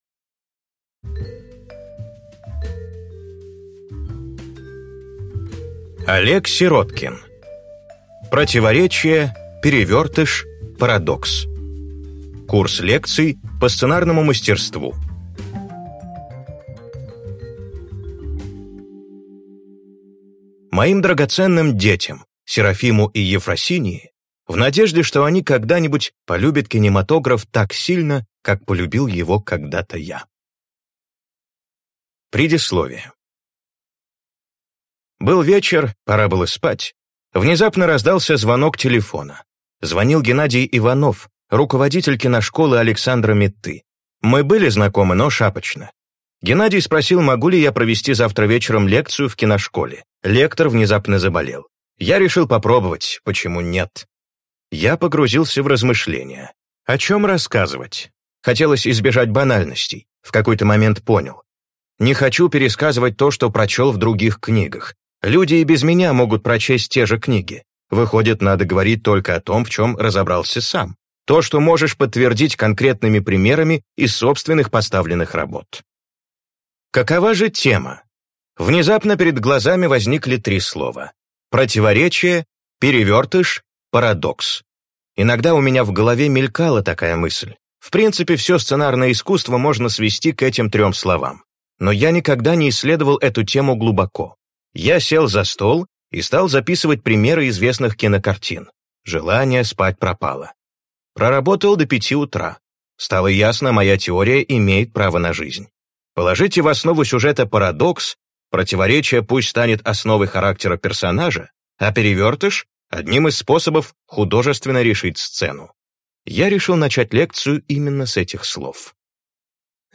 Аудиокнига Противоречие. Перевертыш. Парадокс. Курс лекций по сценарному мастерству | Библиотека аудиокниг